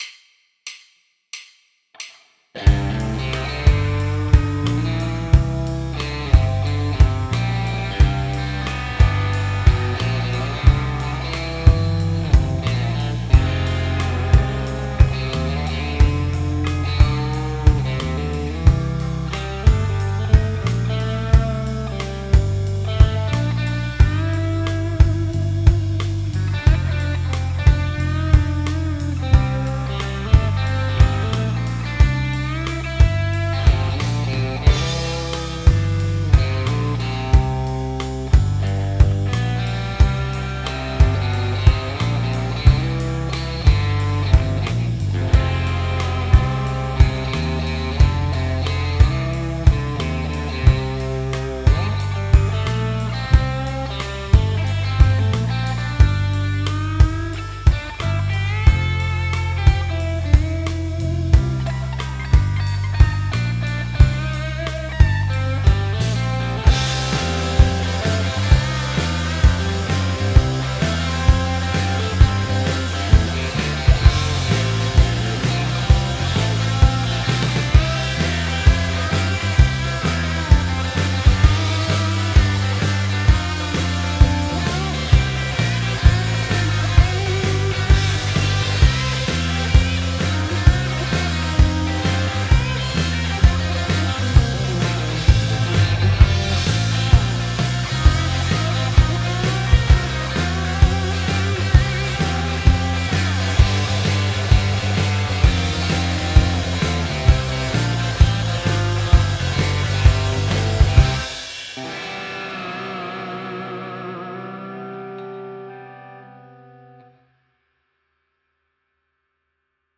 -kaikki rootsmusiikista ja sen soittamisesta pitävät ja kaikkien soitinten taitajat (eli saa vetää millä haluaa) saavat osallistua.
-kun osallistut, soita soolo annetun taustan päälle ja pistä linkki tähän threadiin.